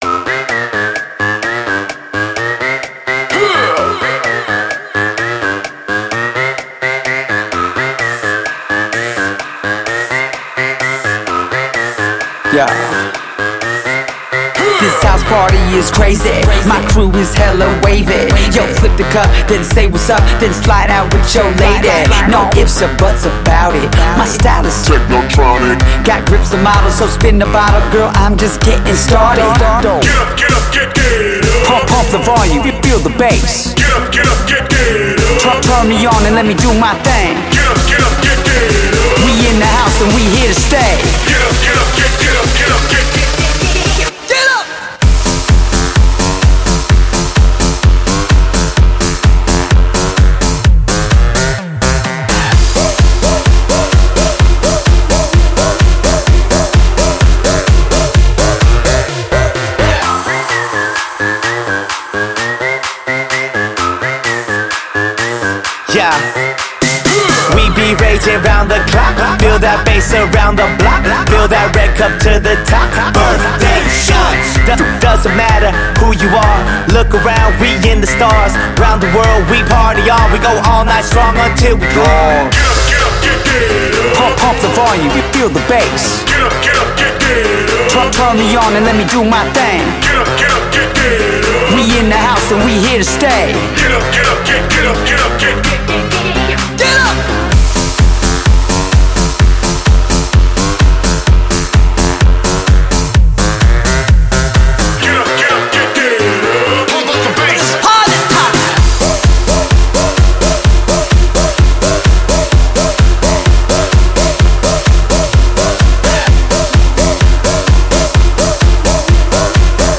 EDM 2000er